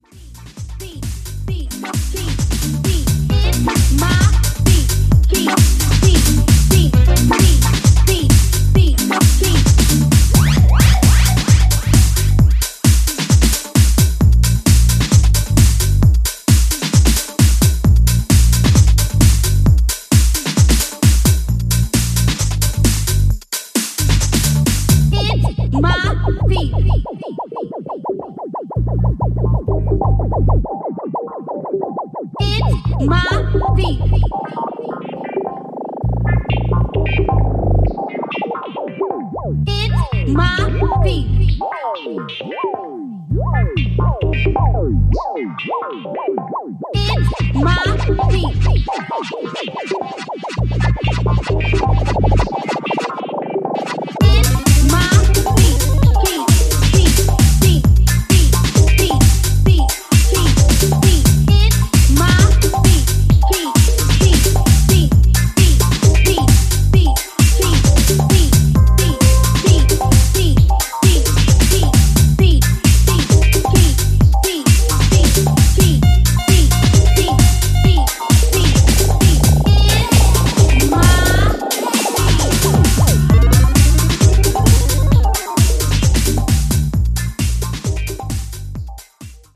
いずれの楽曲もソリッドでカラフル、そしてポジティヴなパワーで溢れたピークタイム・チューン！